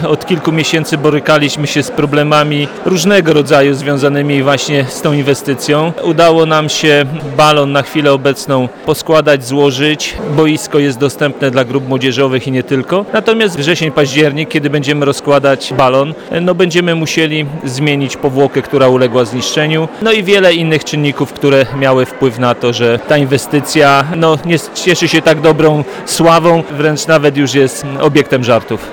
Mówi prezydent Mielca, Jacek Wiśniewski.